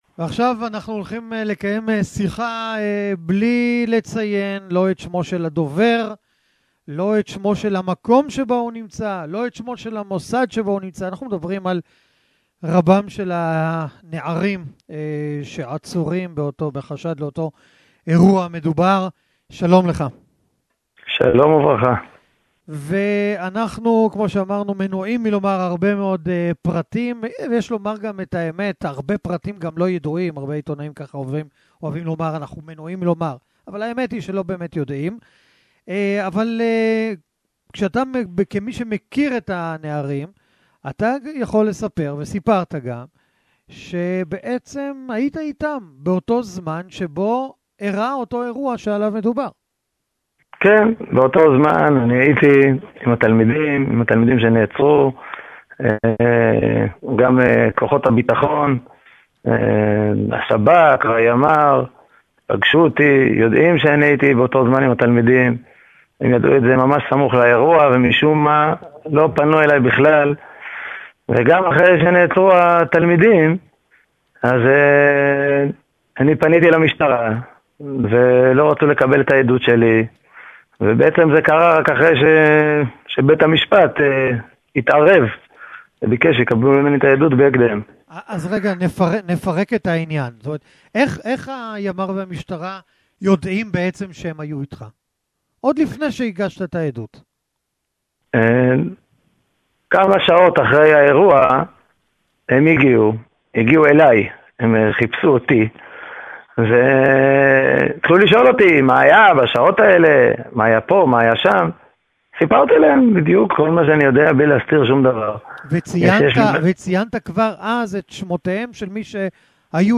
Раввин, чье имя запрещается обнародовать, равно, как и введен запрет на указание места его работы, рассказал в интервью 7 каналу, которое можно послушать